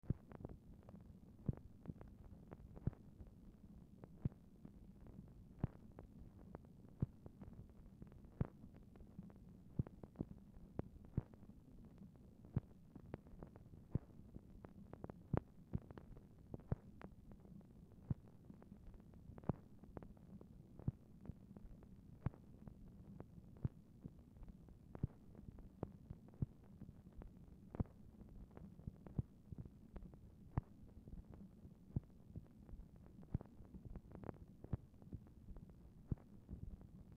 Telephone conversation # 1379, sound recording, MACHINE NOISE, 1/16/1964, time unknown
MACHINE NOISE
Oval Office or unknown location
Telephone conversation
Dictation belt